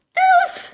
daisy_oof.ogg